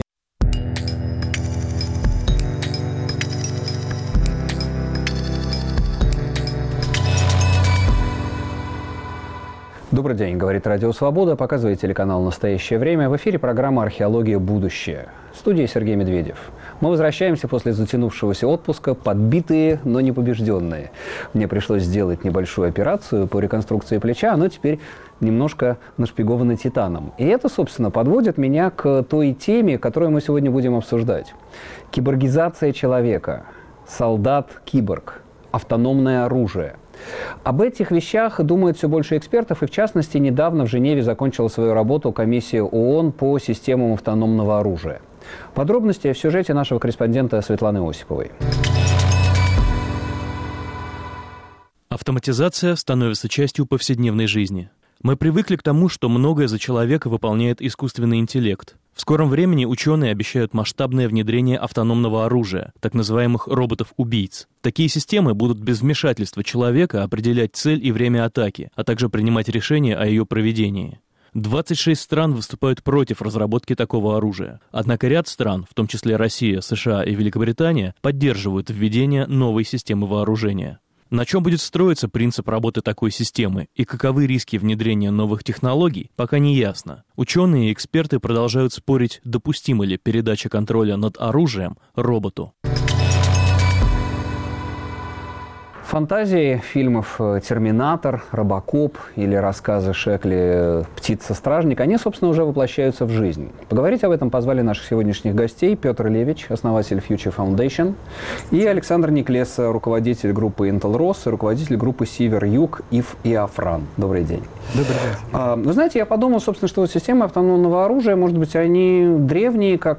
Диалоги и размышления о человеке перед лицом социальных и технологических изменений. Сергей Медведев и его гости в студии Радио Свобода ведут разговор о трендах и технологиях будущего, которое уже наступило.